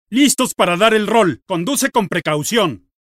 Para celebrar el estreno de la divertida película de Ted 2, Waze –la app de navegación social en tiempo real que obtiene información de las personas que la integran– ofrece a los conductores la opción de poder escuchar las instrucciones de navegación en la voz del malhablado y ultra simpático Ted.